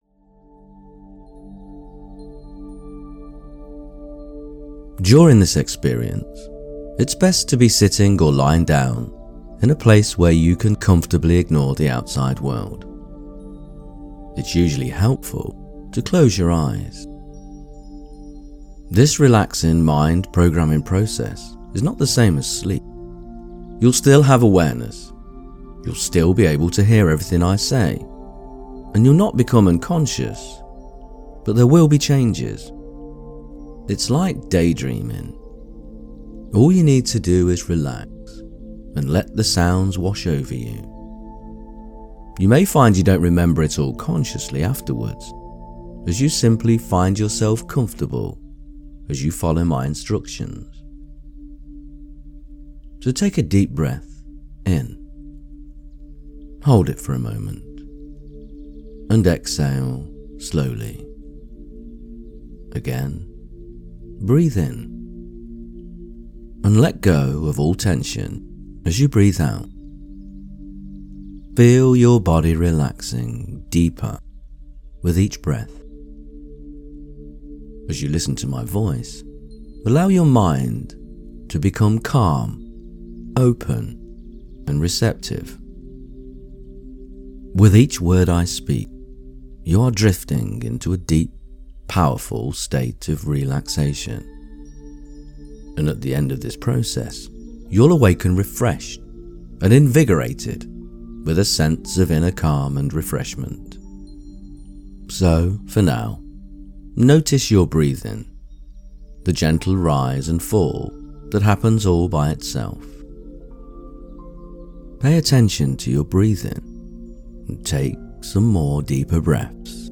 This isn’t just a guided meditation — it’s a spiritual return to your body.
If you’ve been struggling with self-image, emotional eating, shame, or body disconnection… this soft, soul-healing meditation will help you shift. Inside this sacred journey, you’ll visit your Body Connection Chamber, adjust your Inner Alignment Thermostat, and begin to truly love the miracle of your body — not through control, but through kindness, truth, and deep self-respect.
Losing-weight-guided-meditation-manifesting.mp3